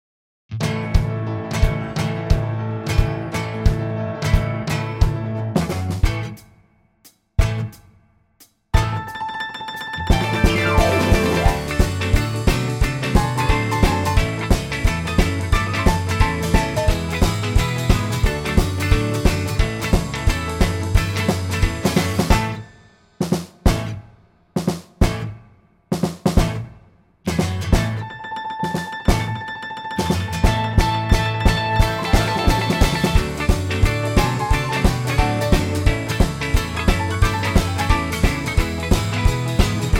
key - A - vocal range - E to G
Superb arrangement